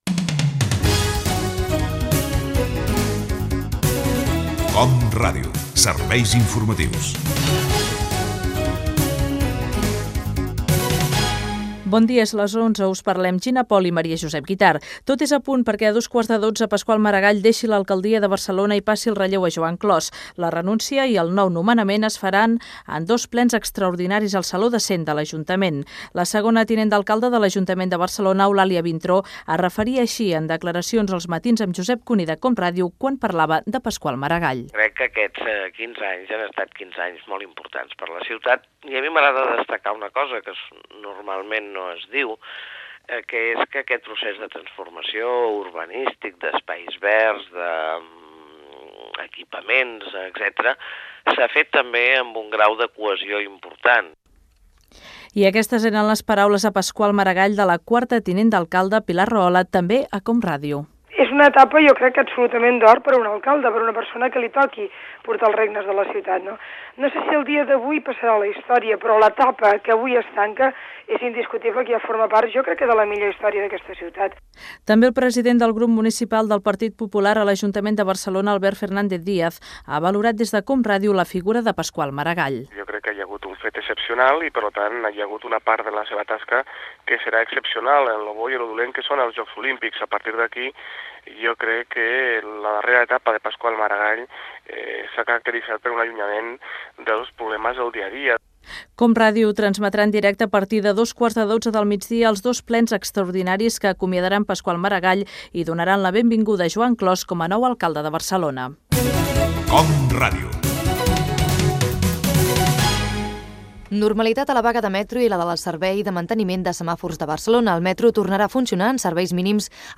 Careta del programa, informació sobre la renúncia de Pasqual Maragall com alcalde de Barcelona i la seva substitució per Joan Clos (Declaracions de Pilar Rahola, Albert Fernández Díaz).
Informatiu
FM